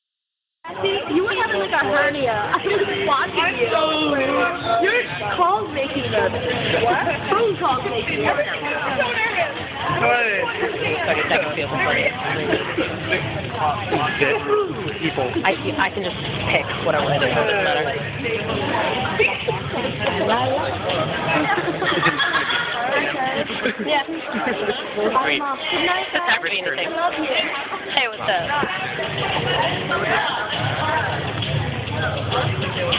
Field Recording #6
Location: Playhouse after War of the Roses Time: 11:45 Date: 3.10.10 WoR Sounds: Various voices and crowds chatting